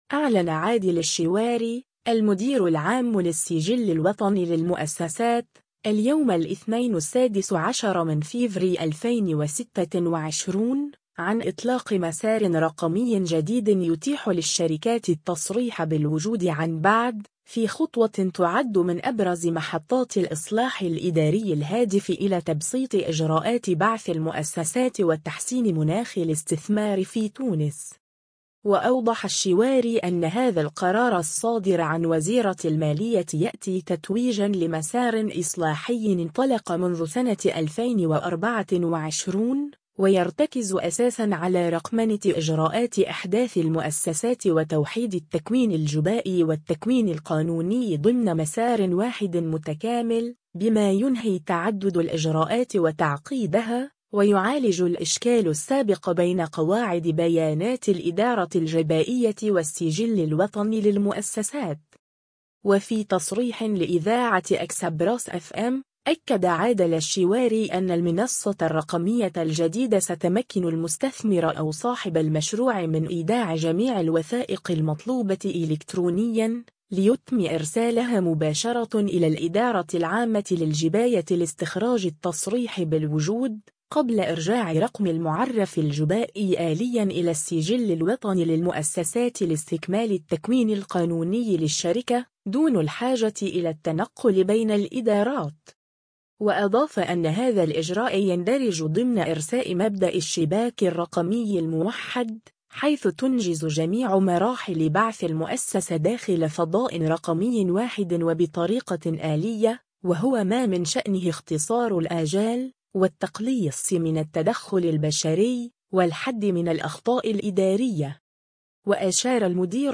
وفي تصريح لإذاعة “إكسبراس أف أم”، أكد عادل الشواري أن المنصة الرقمية الجديدة ستمكّن المستثمر أو صاحب المشروع من إيداع جميع الوثائق المطلوبة إلكترونيا، ليتم إرسالها مباشرة إلى الإدارة العامة للجباية لاستخراج التصريح بالوجود، قبل إرجاع رقم المعرف الجبائي آليا إلى السجل الوطني للمؤسسات لاستكمال التكوين القانوني للشركة، دون الحاجة إلى التنقل بين الإدارات.